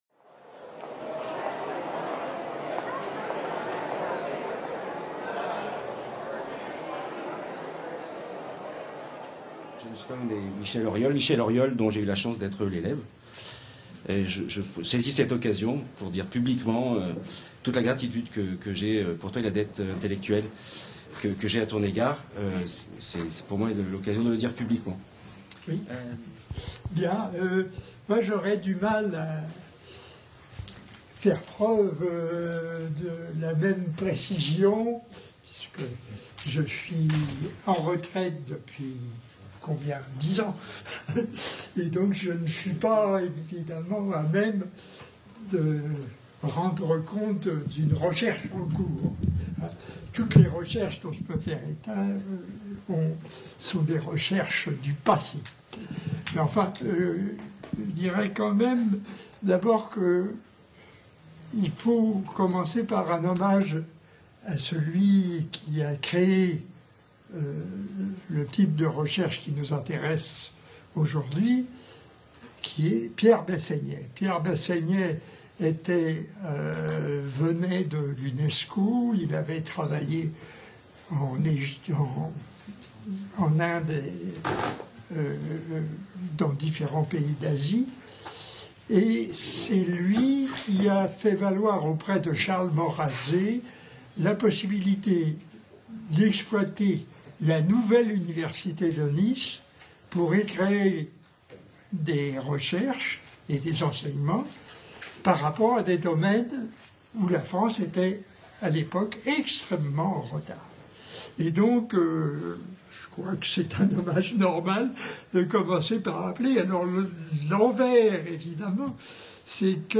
Table ronde : les recherches sur les relations interethniques dans leur contexte théorique et institutionnel : héritages, passerelles, ruptures, discontinuités
Colloque du cinquantenaire de la création du CERIN, Centre d’études des relations interethniques/ Ideric, Institut d’études et de recherches interethniques et interculturelles 3 et 4 mai 2017, MSHS, Université Nice Sophia Antipolis